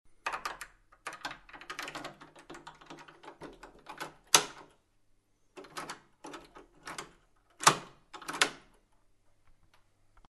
Звуки дверного замка
Звук захлопывания двери ключом